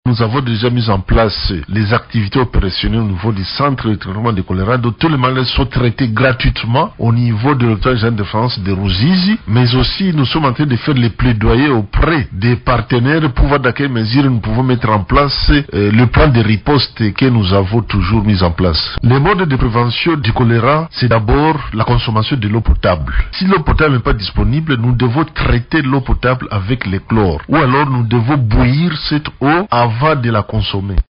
dans un point de presse organisé sur place à Ruzizi